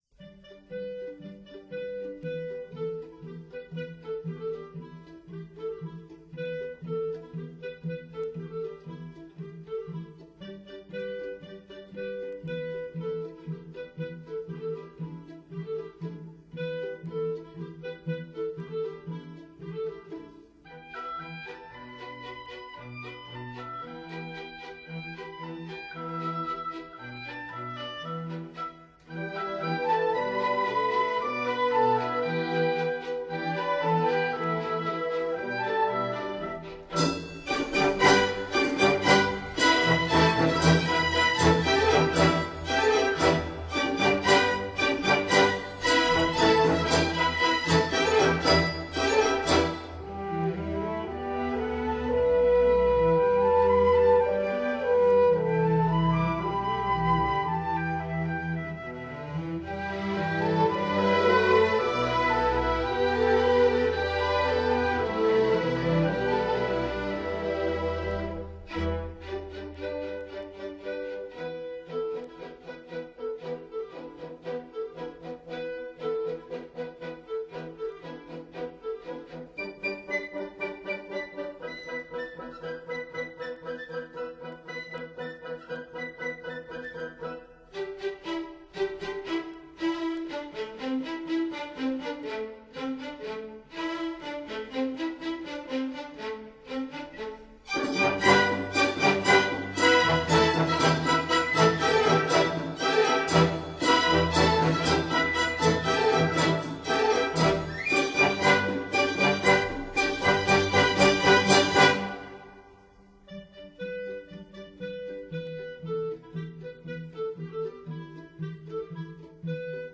大型管弦乐队+国内器乐演奏名星+联诀奉献
西洋管弦强大表现力